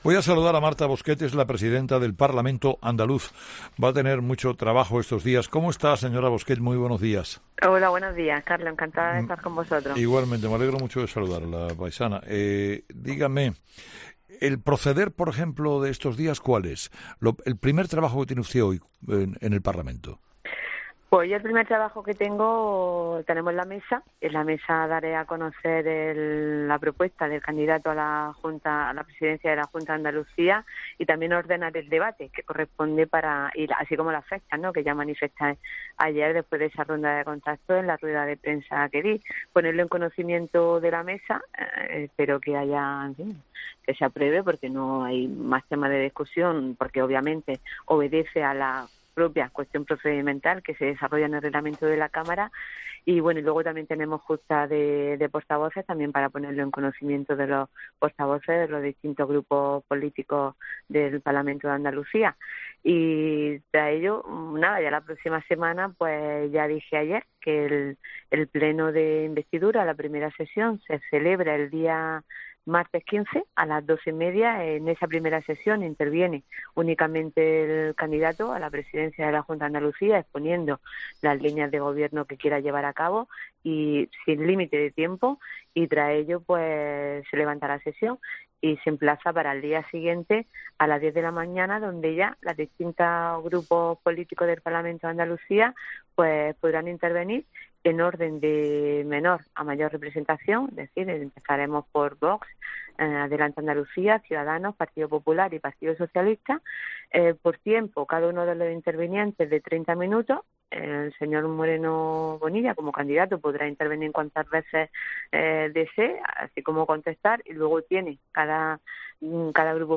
Entrevista a Marta Bosquet